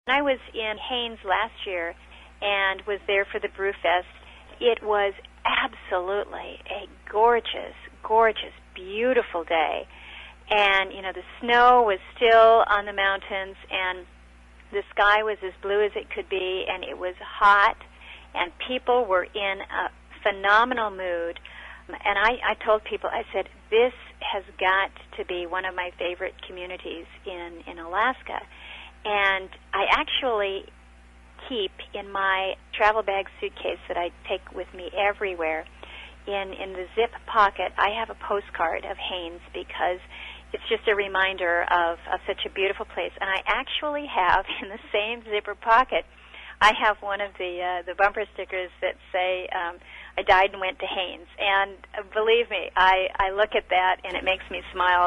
Alaska Senator Lisa Murkowski stayed with us and was recently interviewed on our local radio station.